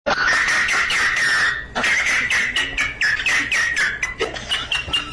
Memes
Dog Laughing At You